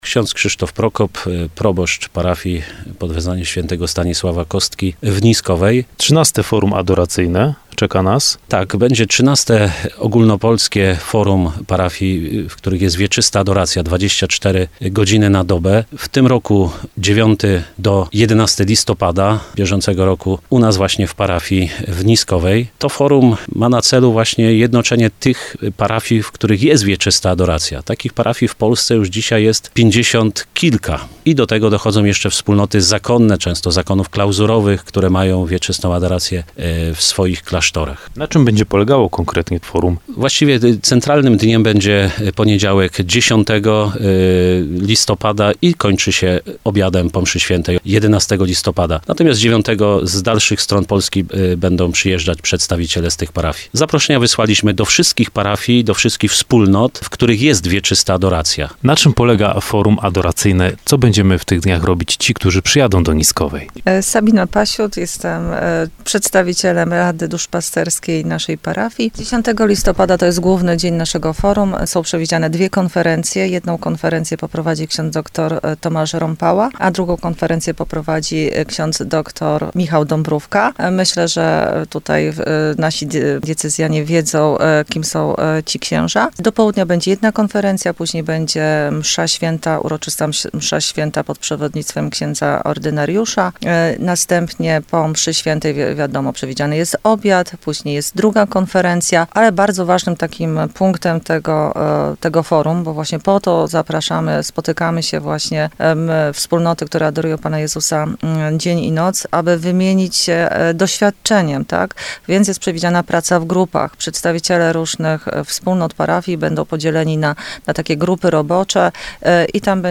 7rozmowa_Wieczysta_Adoracja_Forum.mp3